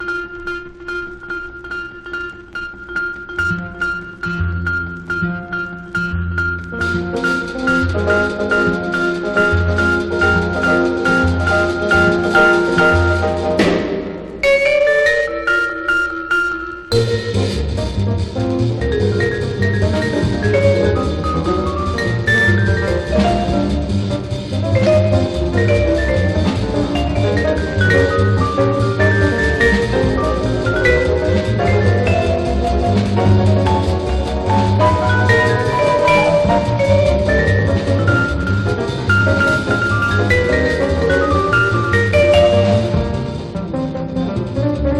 Jazz, Swing　USA　12inchレコード　33rpm　Mono
ジャケスレ汚れ　盤スレキズ　盤の材質によるノイズ有